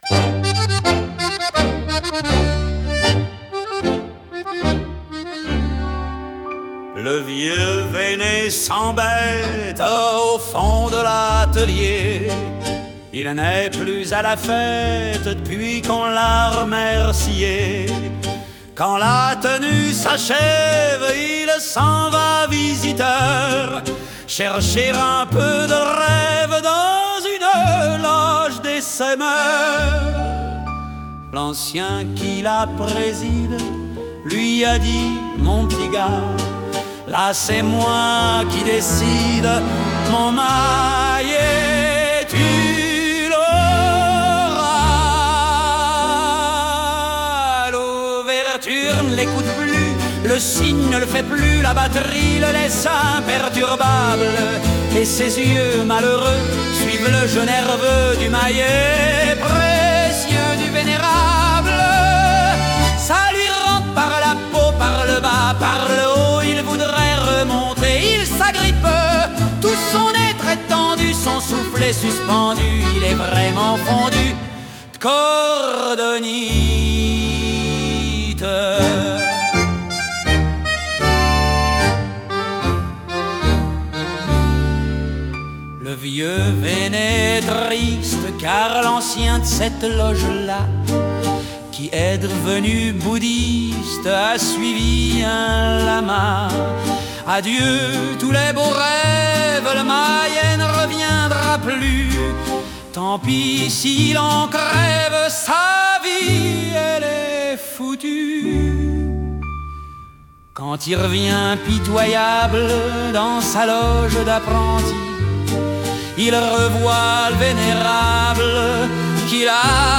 Enregistrement public festival d’Humour 2013